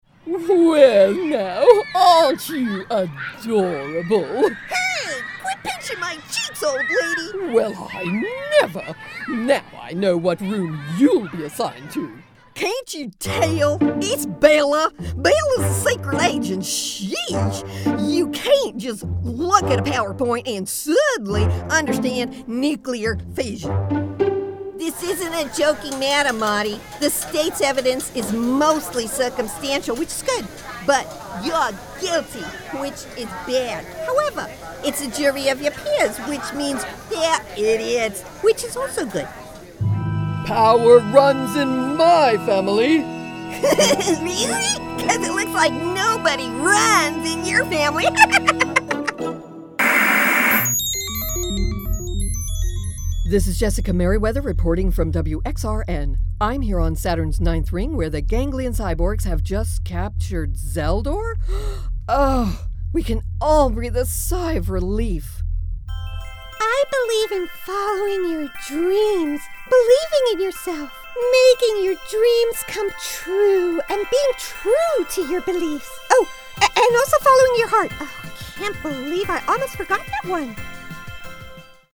Versatile, friendly, confident.
Character Demo
I have a state-of-the-art professional home studio.